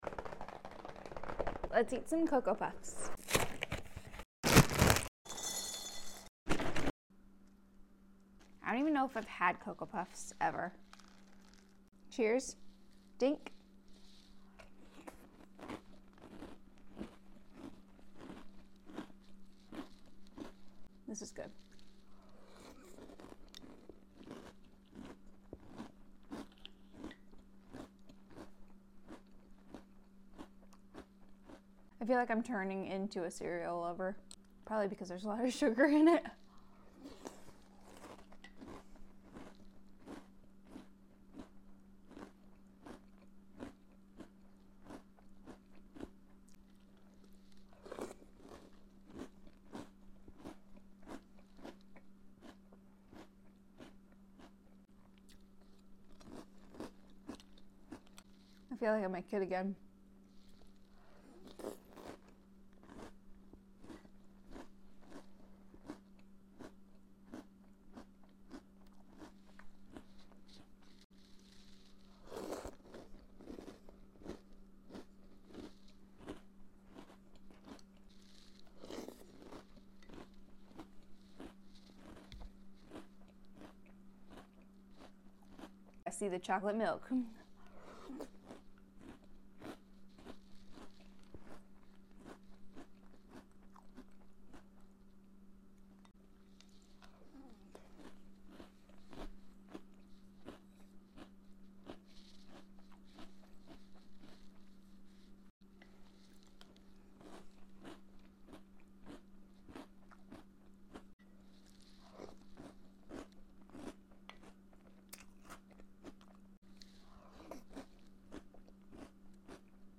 Cocoa Puffs Cereal Mukbang 🥣 Sound Effects Free Download